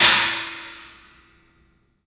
Index of /90_sSampleCDs/E-MU Producer Series Vol. 5 – 3-D Audio Collection/3DPercussives/3DPACymbals